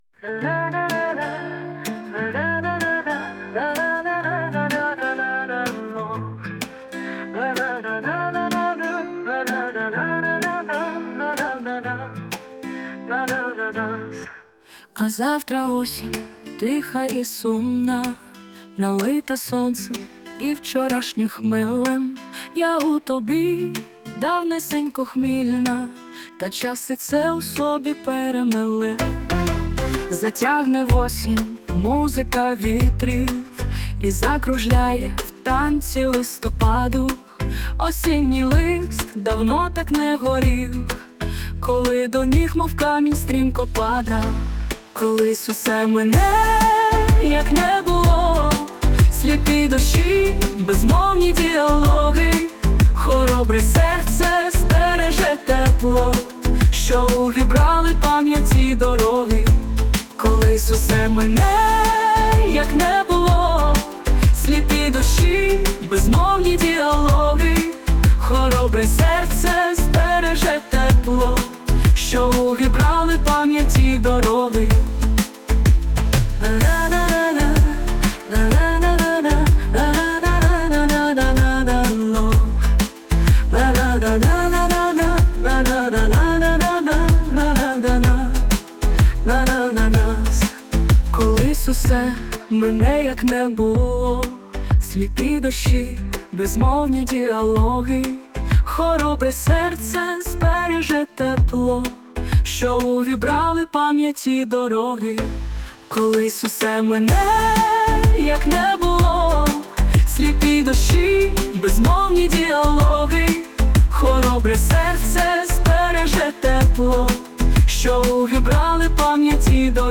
Пісня створена в нейромережі
СТИЛЬОВІ ЖАНРИ: Ліричний
приспів фантастичний ...
настрій чудовий